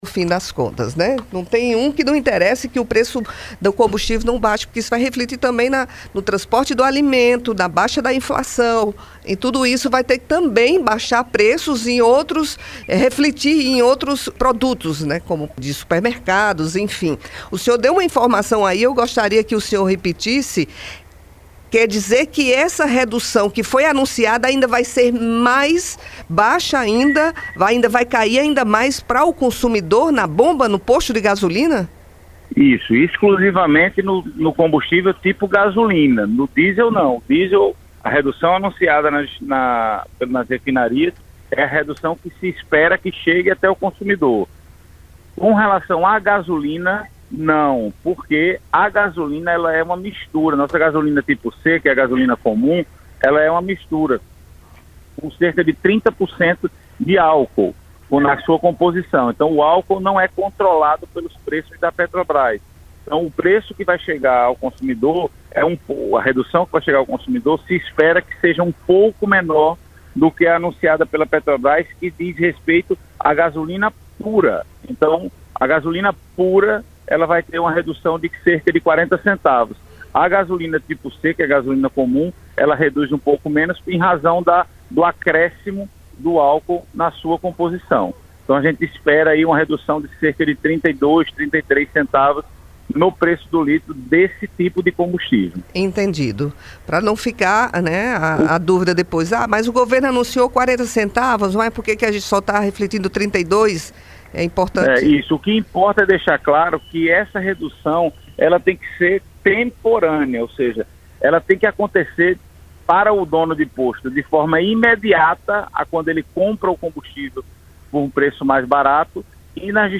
[ÁUDIO-ENTREVISTA] Secretário do Procon-JP esclarece como se dará a redução e fiscalização de preços nos combustíveis na capital
(por telefone), no programa Revista 88